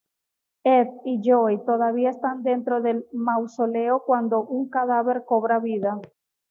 Read more cadaver, corpse Frequency B1 Hyphenated as ca‧dá‧ver Pronounced as (IPA) /kaˈdabeɾ/ Etymology Borrowed from Latin cadāver In summary Borrowed from Latin cadāvere.